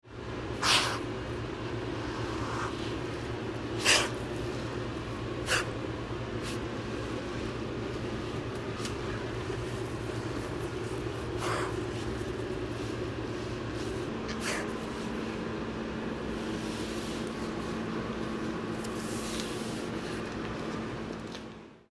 A recording of the hissing of an upset captive adult courtesy of the Arizona-Sonora Desert Museum.